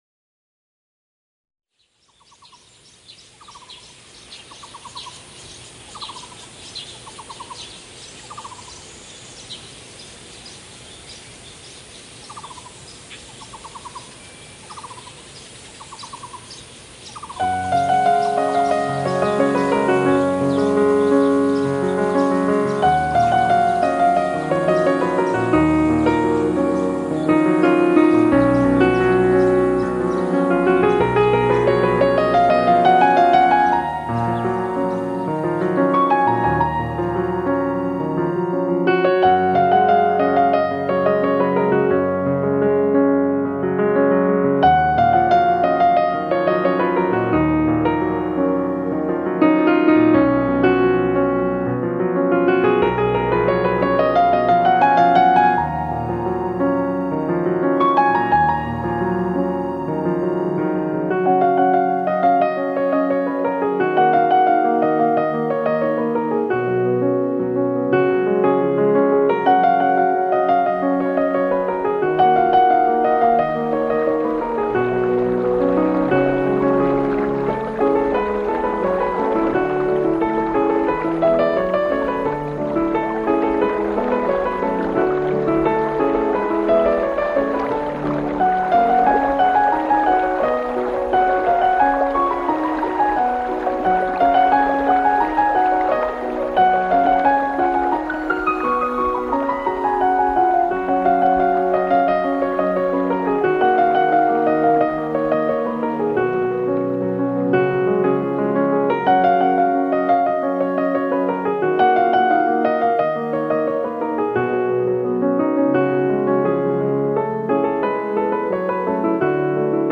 由鋼琴和山林裡的自然聲音交融而成
清清淡淡的音樂風格，充滿了山居生活的自然氣息
大量採用印象派的作曲手法
使音樂有時聽來像波光粼粼的湖濱散記